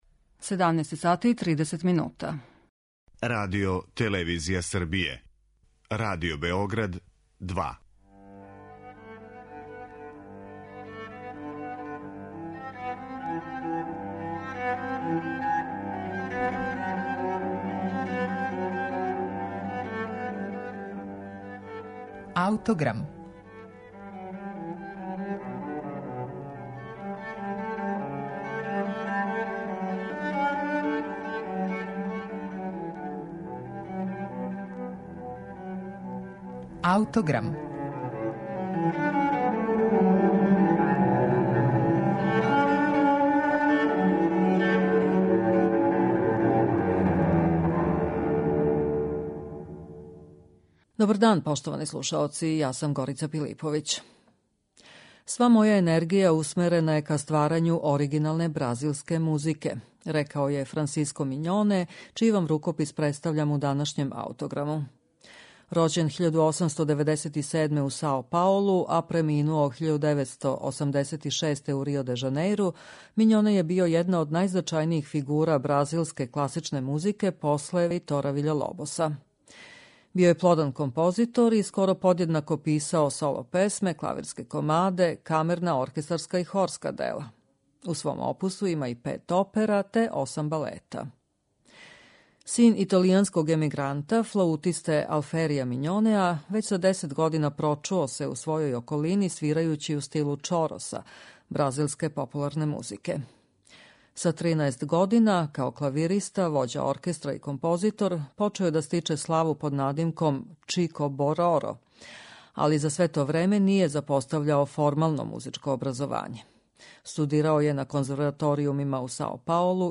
Етиде за гитару